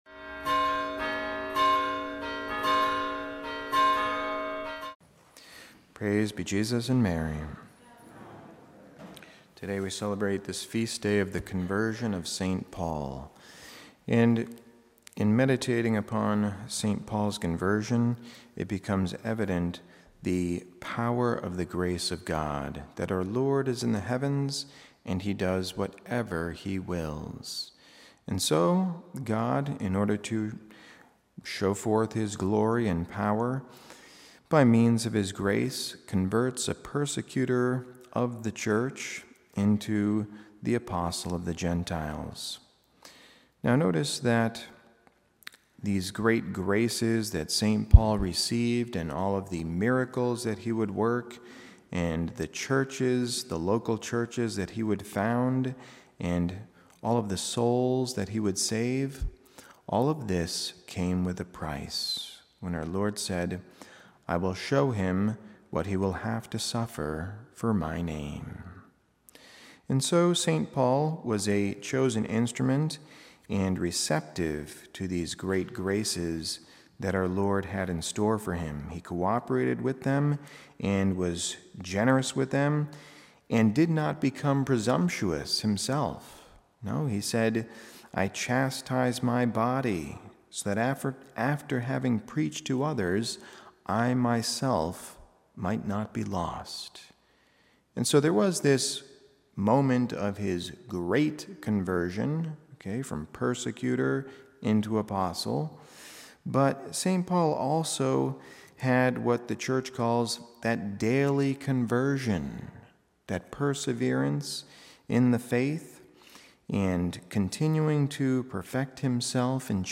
Homily
Mass: Conversion of St. Paul - Feast Readings: 1st: act 22:3-16 Resp: psa 117:1, 2